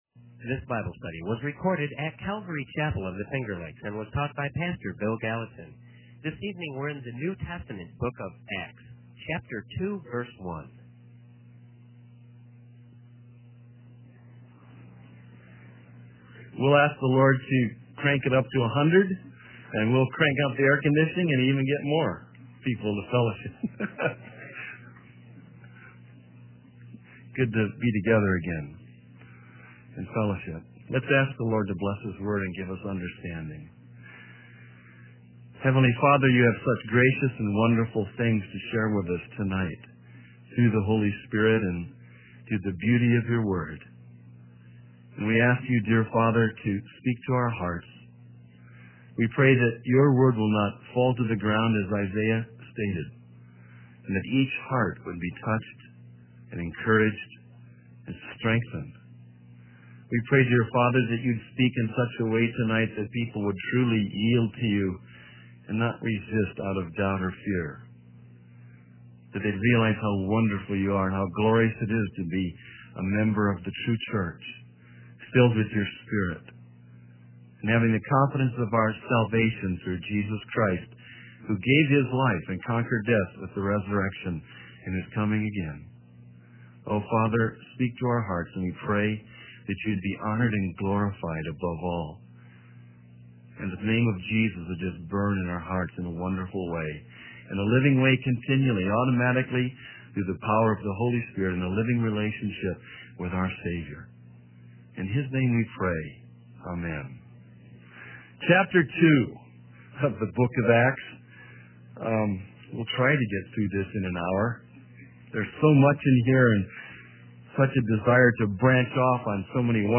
He teaches from Acts chapter 2, highlighting the power and availability of salvation through Jesus Christ. The sermon concludes with a call to surrender to God and gladly follow Him in obedience.